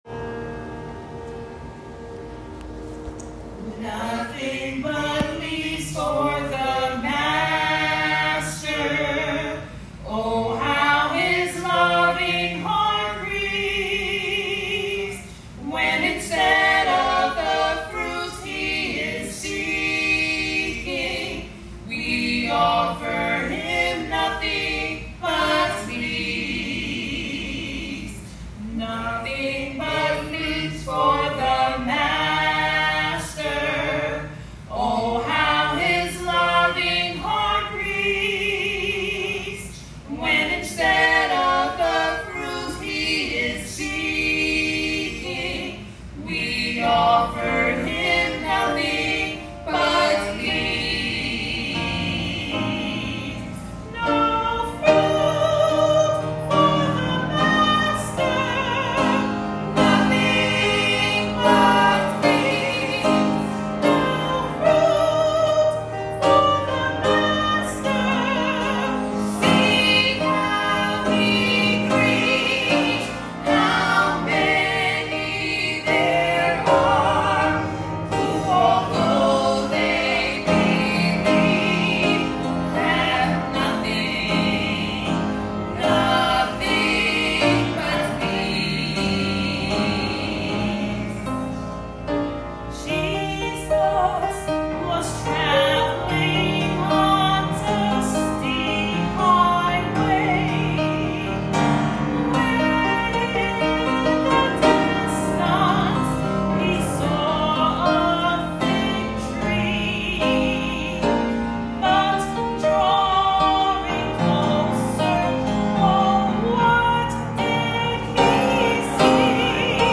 Sermons - Arverne Church of God